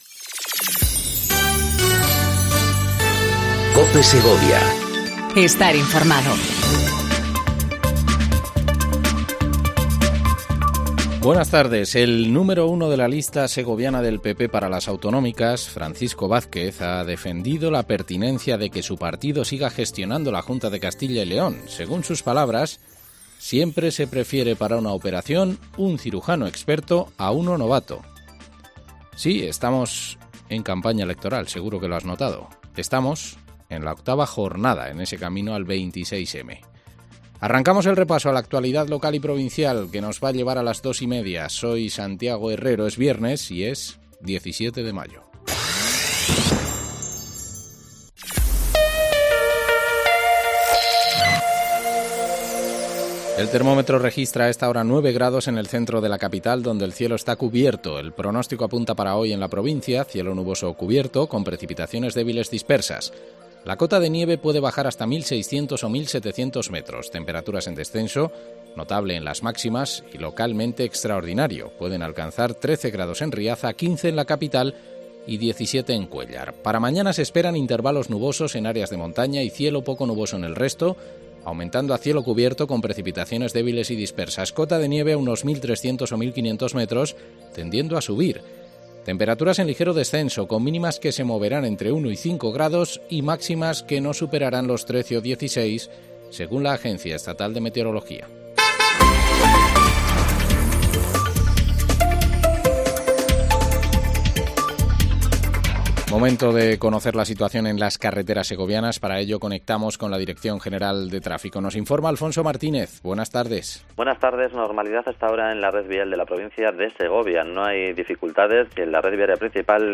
INFORMATIVO DEL MEDIODÍA EN COPE SEGOVIA 14:20 DEL 17/05/19